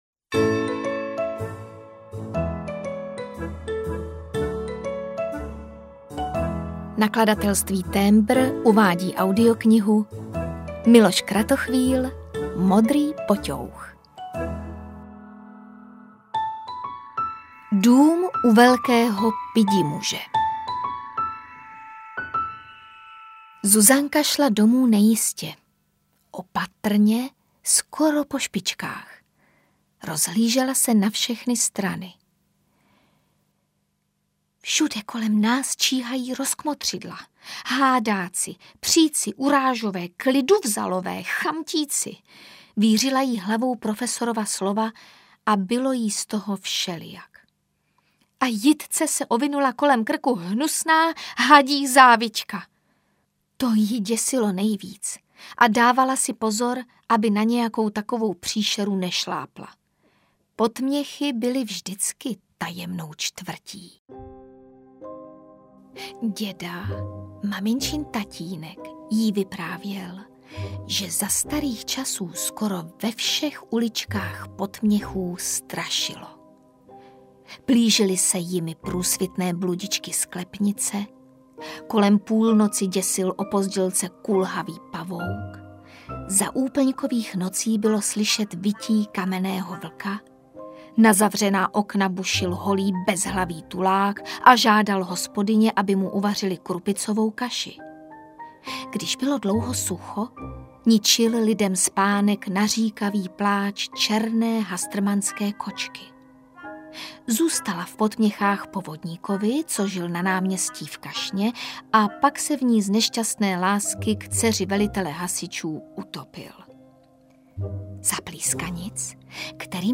Modrý Poťouch audiokniha
Ukázka z knihy